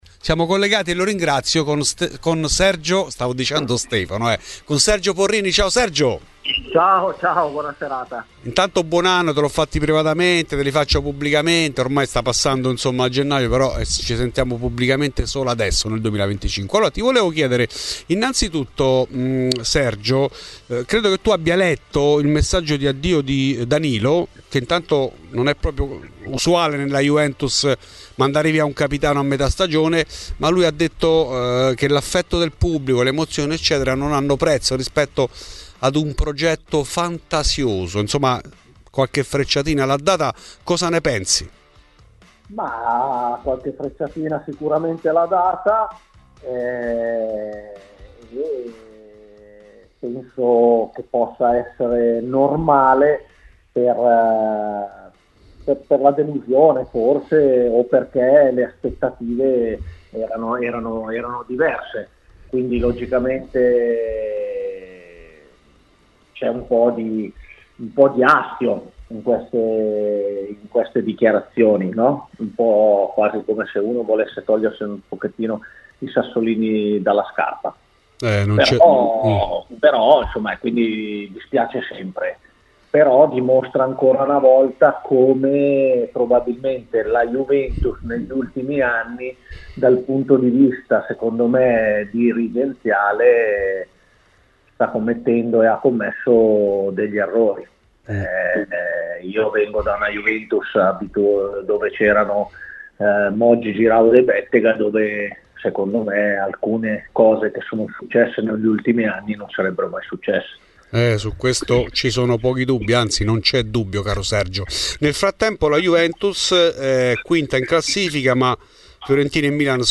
Danilo ha detto addio con un post pieno di emozioni, ma anche molto polemico nei confronti di Thiago e società. Di questo ed altro ha parlato in ESCLUSIVA a Fuori di Juve l'ex difensore bianconero, ora allenatore, Sergio Porrini.